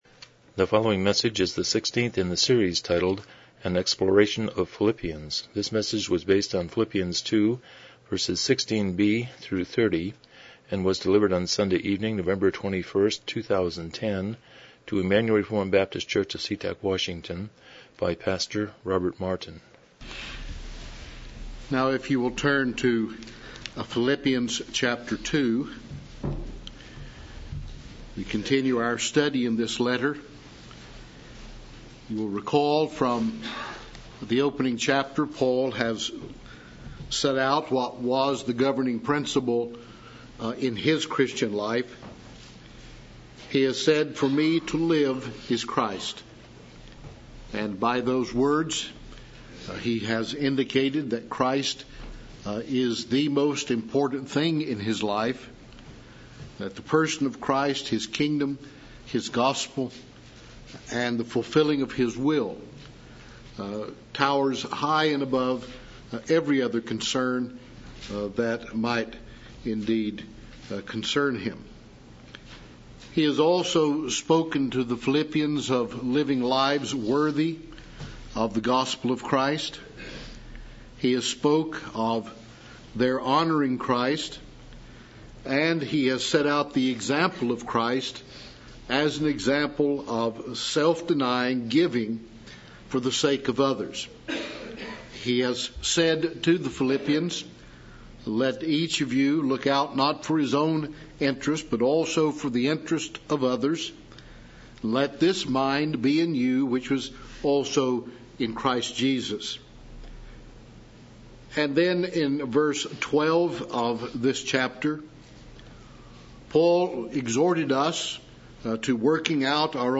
Philippians 2:16-30 Service Type: Evening Worship « 124 Romans 9:25-29